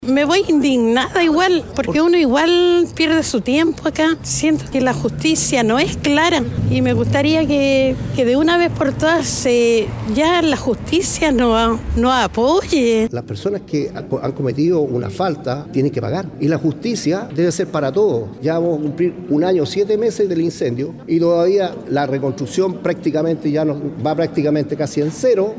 En la instancia estuvieron presentes damnificados del siniestro, quienes esperan celeridad en lo que resta de la causa.
cu-cierre-investigacion-mix-damnificados-.mp3